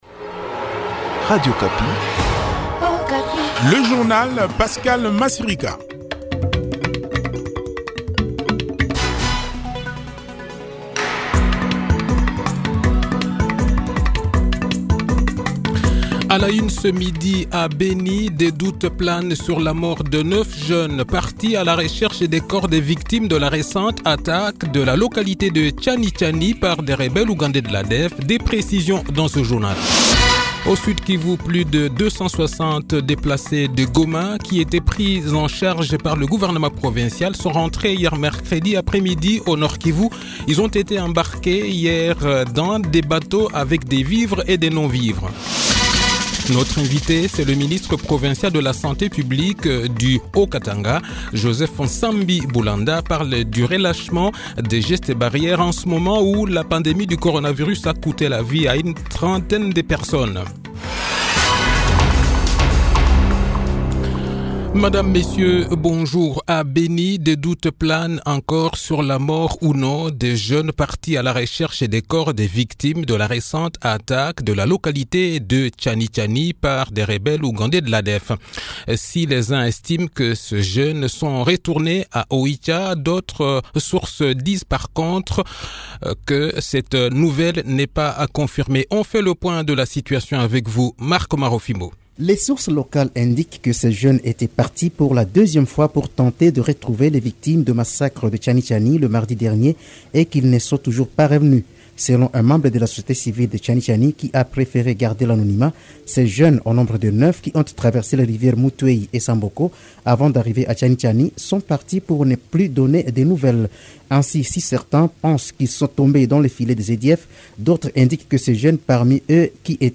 Le journal de 12 h, 10 Juin 2021